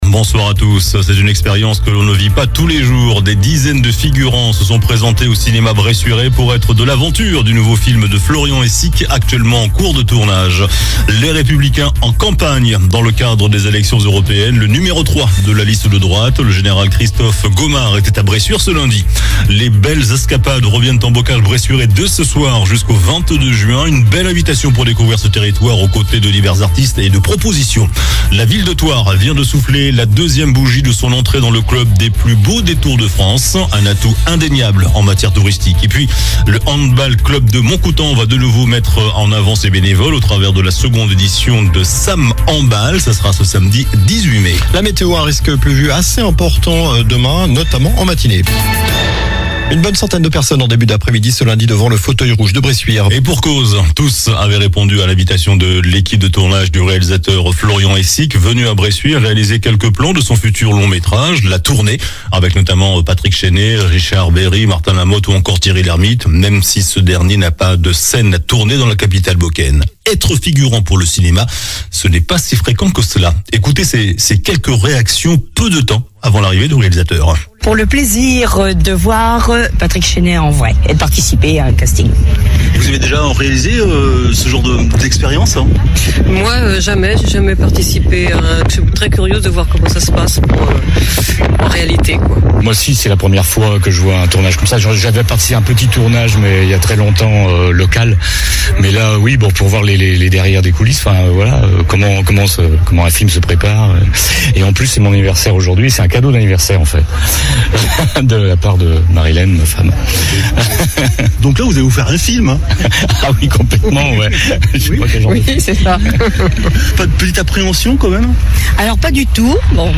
Journal du lundi 13 mai (soir)